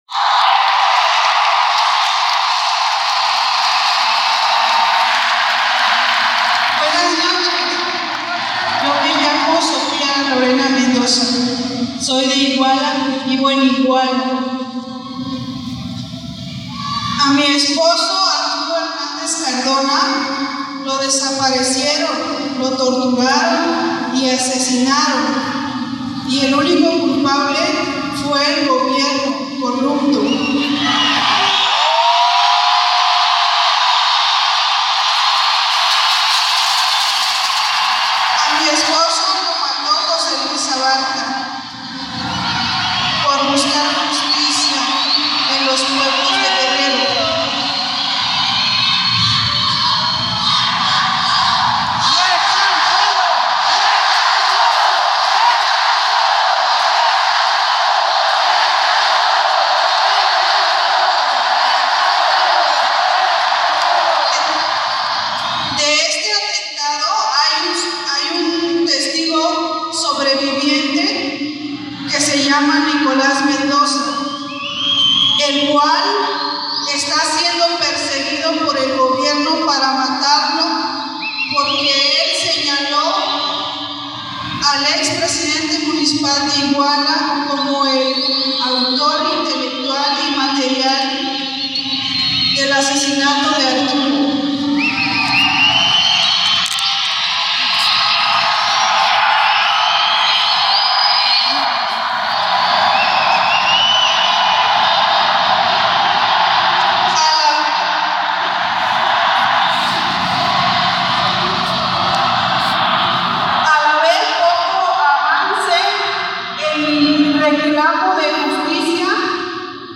Calle 13 en México: fotos y reseña del concierto y el audio completo incluido del discurso de los padres de los desaparecidos en Ayotzinapa.